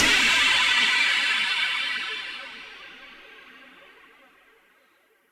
Index of /musicradar/impact-samples/Processed Hits
Processed Hits 04.wav